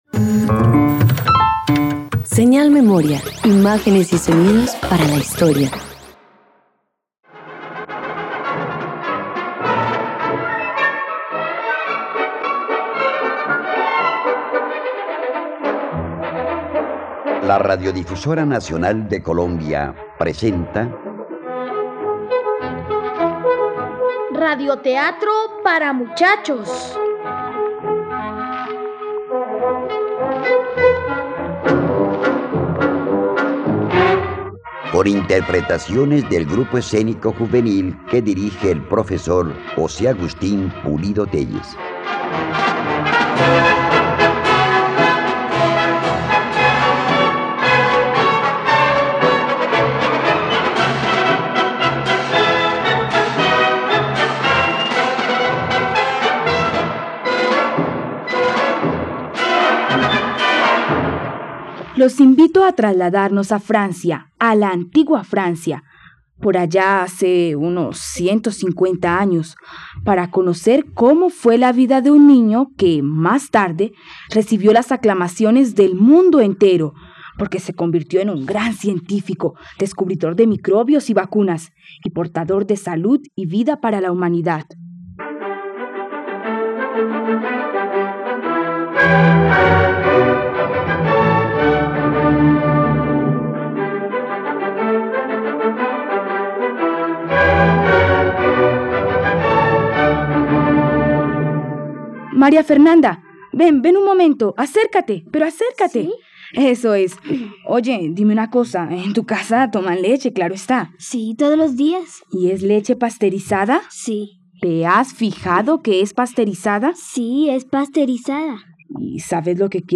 Louis Pasteur - Radioteatro dominical | RTVCPlay